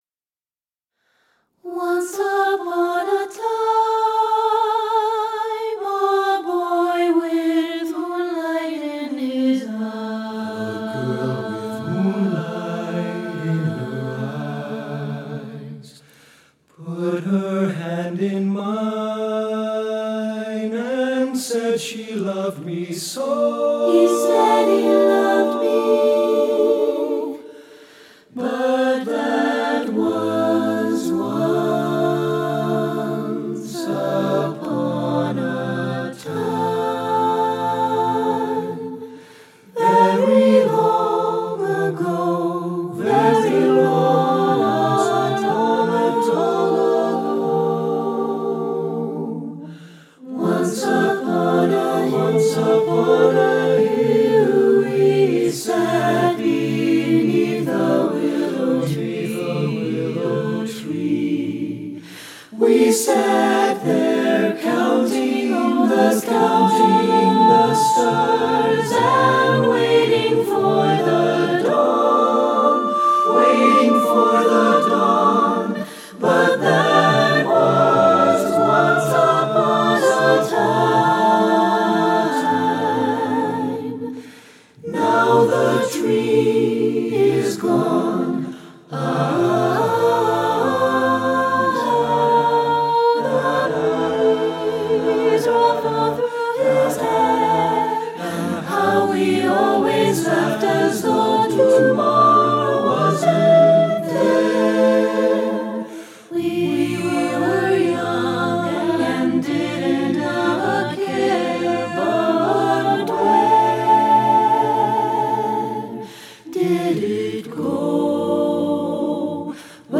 choral
SATB divisi, a cappella, sample